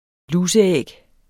Udtale [ ˈlusə- ]